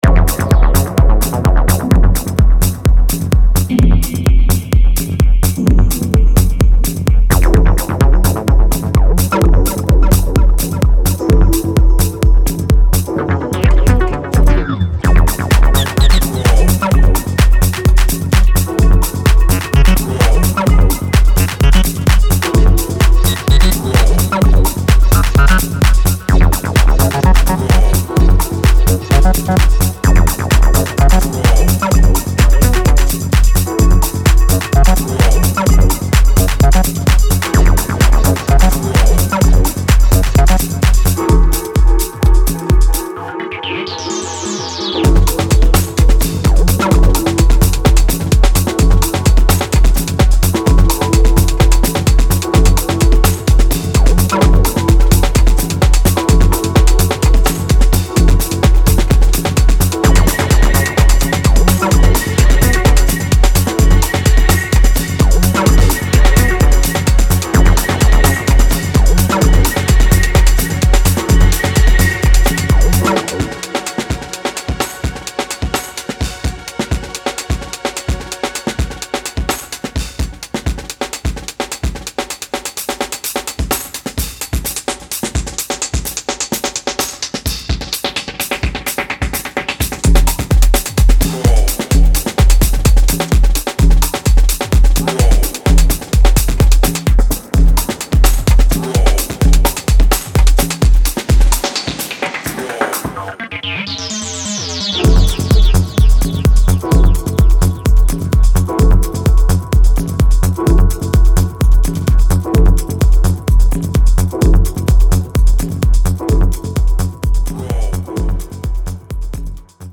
テクニカルなブレイクビートの刻みと表情豊かなアシッドシンセにハメられる